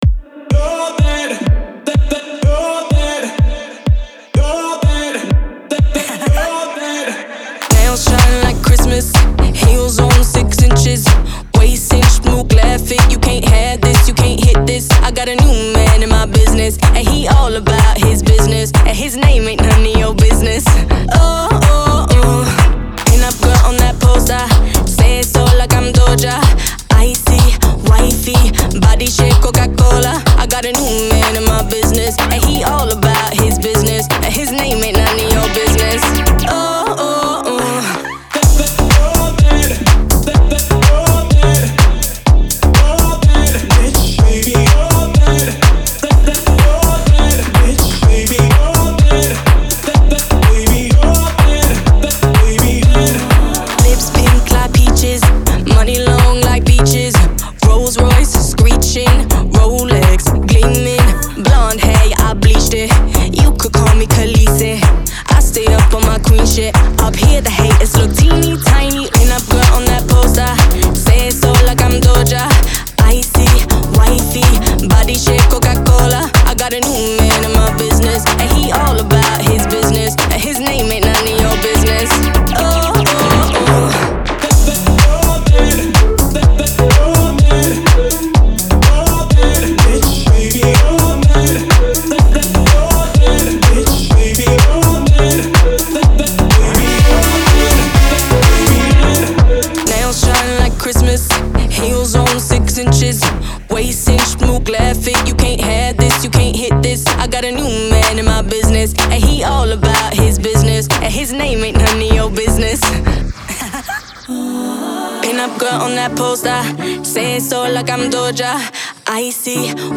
это энергичный трек в жанре поп с элементами хаус-музыки
британской певицей